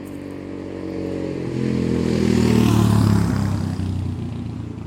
驶过的摩托车
描述：在华为P10智能手机上录制的公路上通过摩托车的现场录音。 Audacity声音被放大了。
Tag: 摩托车 运输 传递 摩托车 高速公路 旅游 路过车辆 现场录音